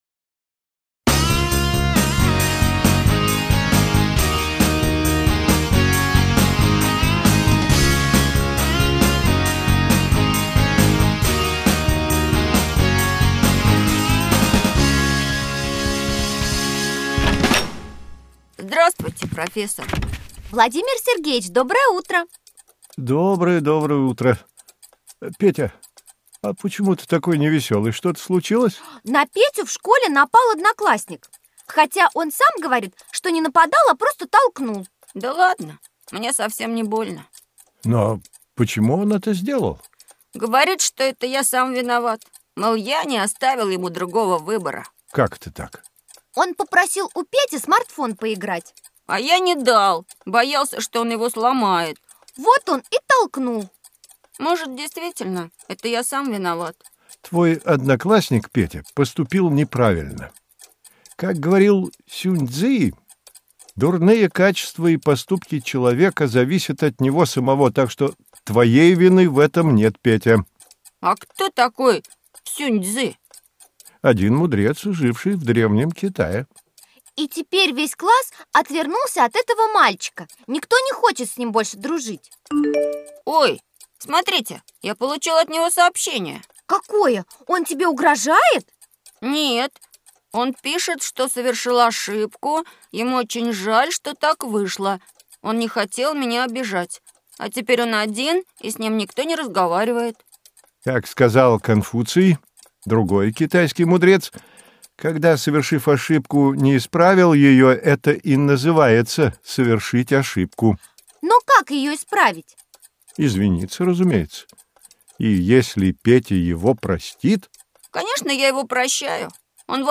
Аудиокнига Развивающая аудиоэнциклопедия. История. Путешествие в Древний Китай | Библиотека аудиокниг